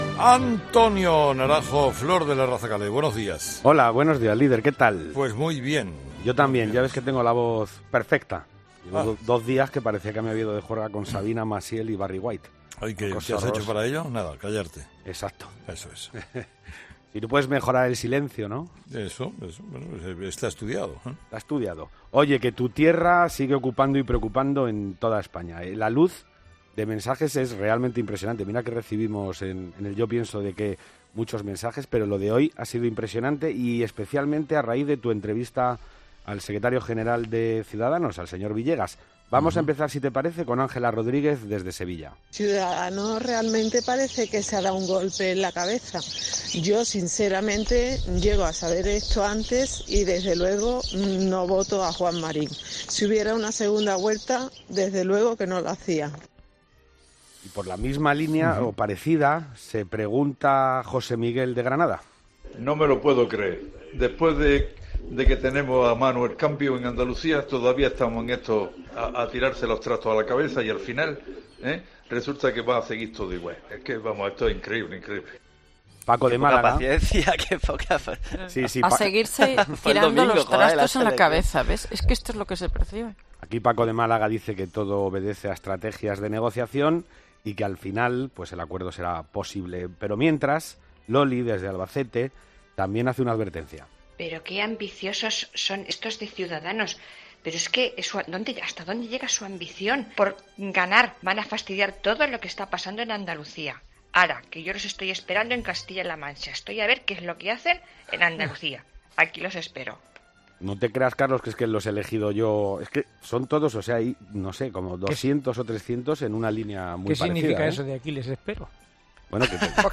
Los seguidores de Carlos Herrera se convierten en un tertuliano más gracias a este espacio que les brinda Herrera en Cope.